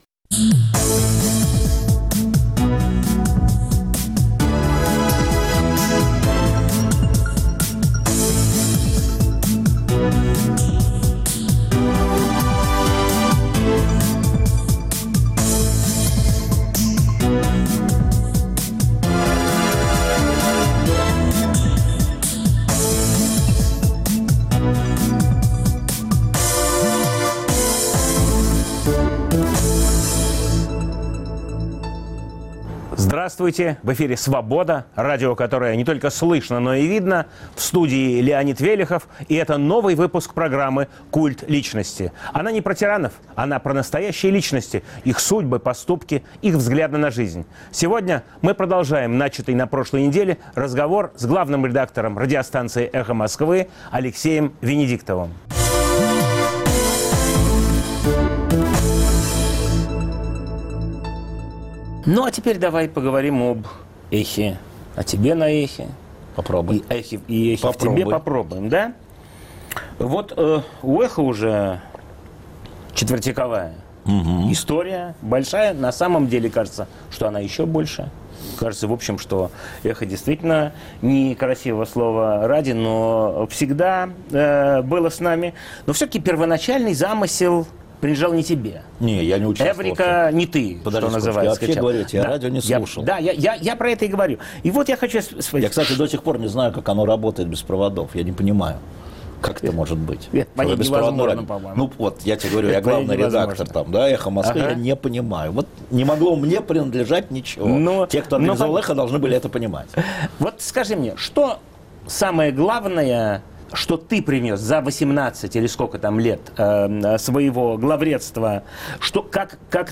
Программа о настоящих личностях, их судьбах, поступках и взглядах на жизнь. В студии главный редактор радио "Эхо Москвы" Алексей Венедиктов.